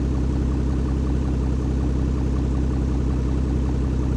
rr3-assets/files/.depot/audio/Vehicles/v12_01/v12_01_idle.wav
v12_01_idle.wav